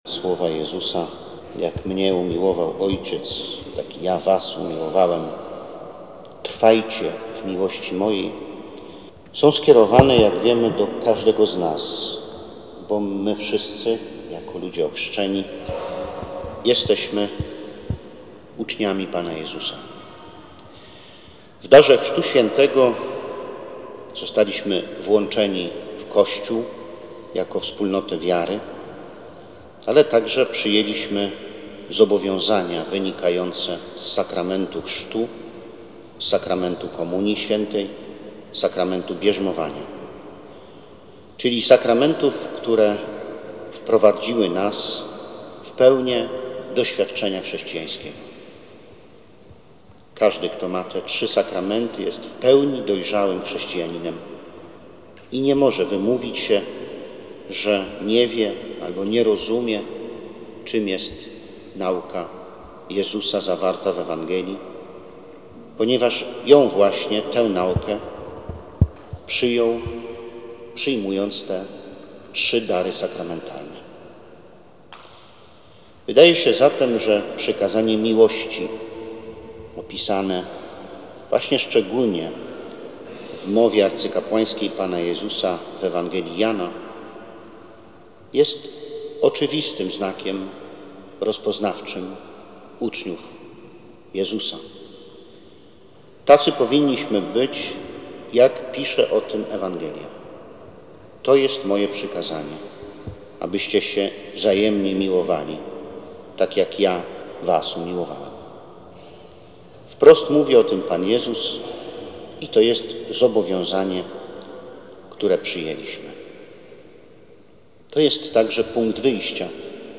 Pięciu alumnów Wyższego Seminarium Duchownego Diecezji Warszawsko-Praskiej, 20 maja 2023 roku, przyjęło święcenia diakonatu. Eucharystię połączoną z obrzędem święceń odprawił biskup Jacek Grzybowski, w konkatedrze Matki Bożej Zwycięskiej na stołecznym Kamionku.